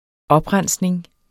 Udtale [ -ˌʁanˀsneŋ ]